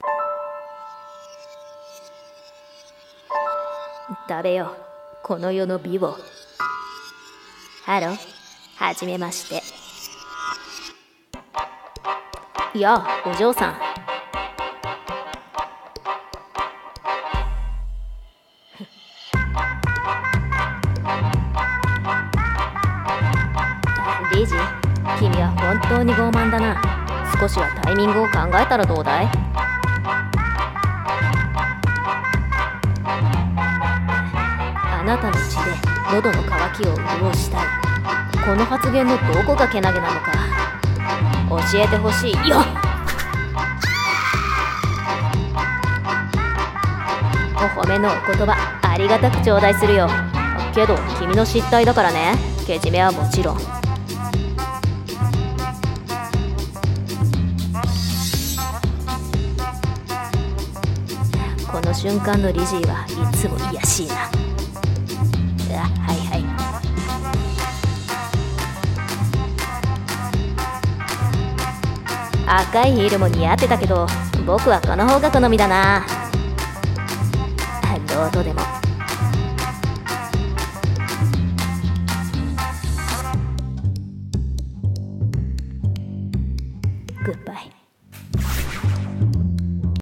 【声劇】Hello. Please die 【掛け合い】